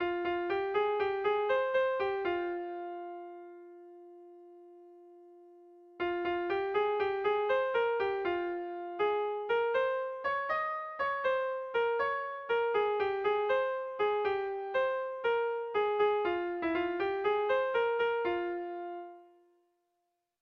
Erromantzea
Kopla handiaren moldekoa
AABDE